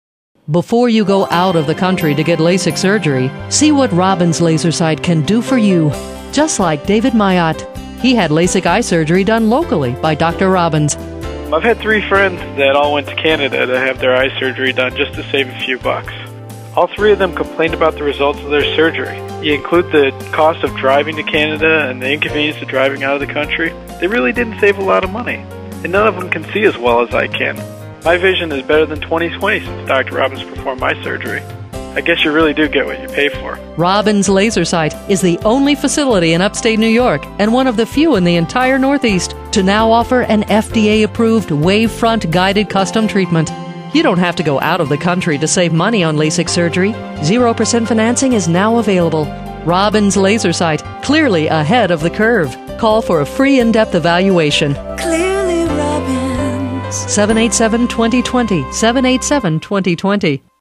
Radio Ad - Canada